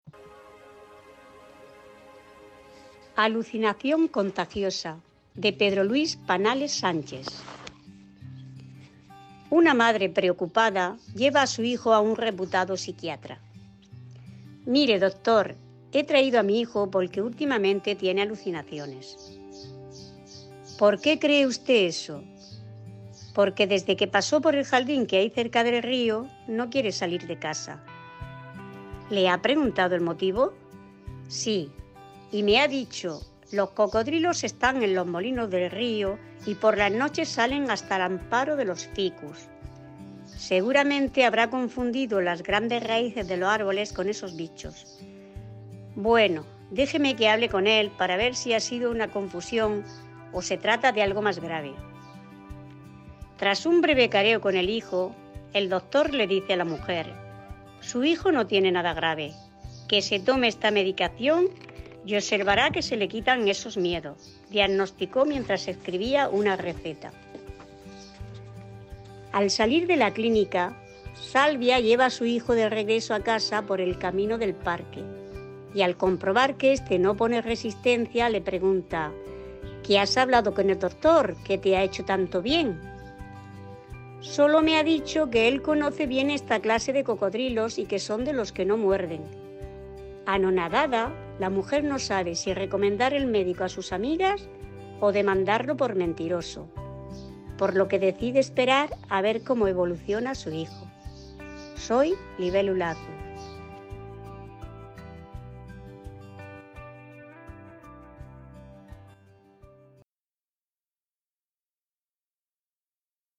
Modalidad cuento
con la lectura de “Alucinación contagiosa” (Pedro Luís Panalés Sánchez).